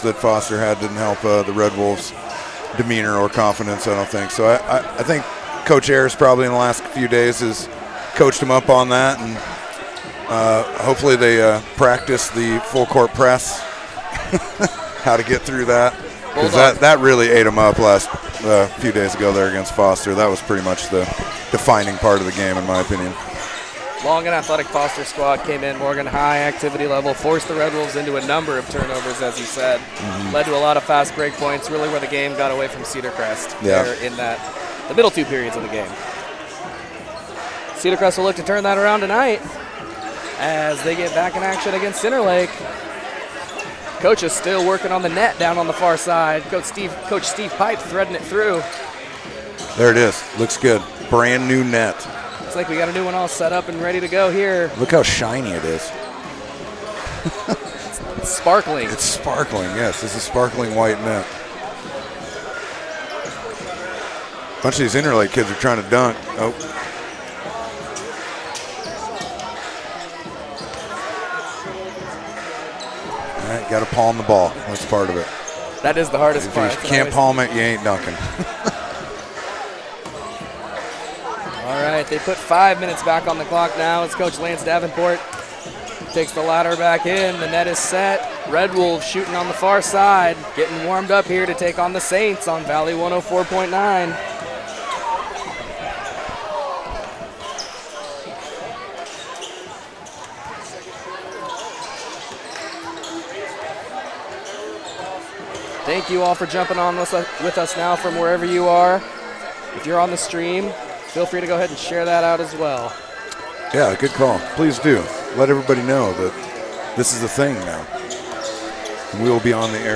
Cedarcrest vs. Interlake - HS Basketball - 01.31.26